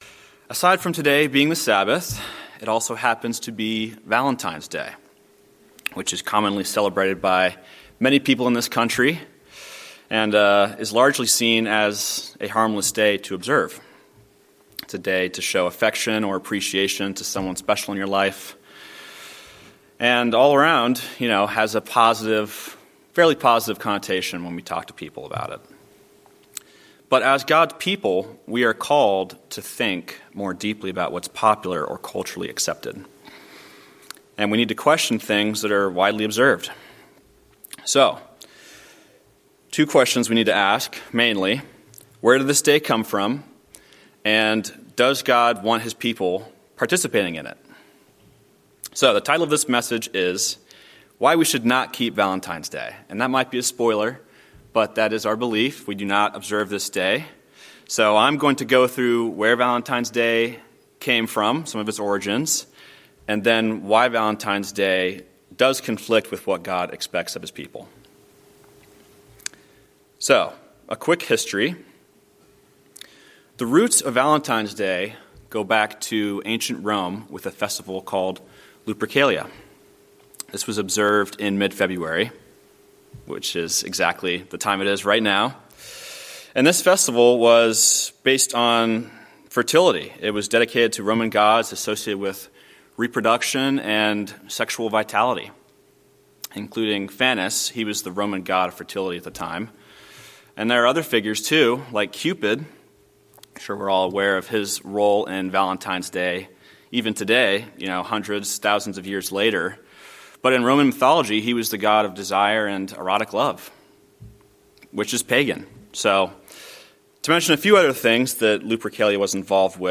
Sermons
Given in Atlanta, GA Buford, GA